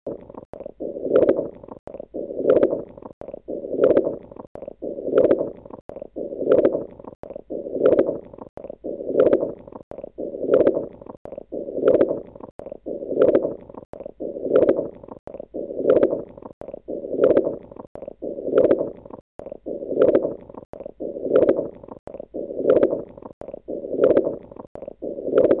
Auscultación
Pudieran escucharse mejor con la campana del estetoscopio.
Renal_artery_bruit_in_lower_left_quadrant.mp3